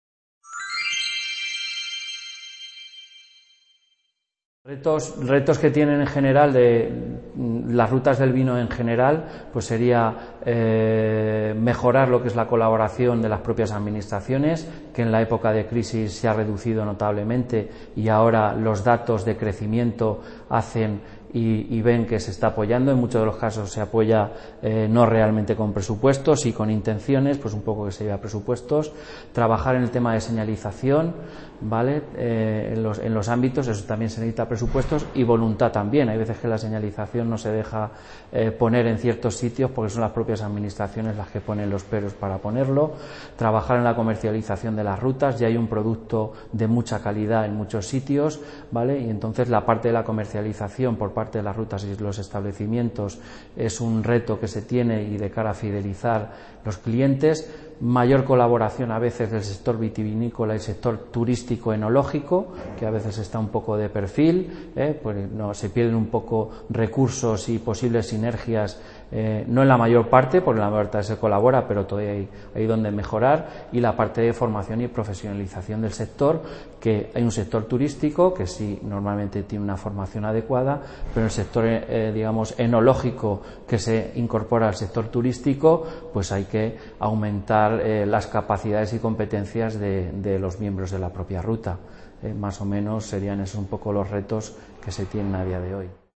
C.A. Ponferrada - II Congreso Territorial del Noroeste Ibérico